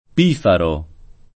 piffero [p&ffero] s. m. — per lo strumento e il sonatore, antiq. o pop. pifero [p&fero], e più ant. piffaro [p&ffaro] o pifaro [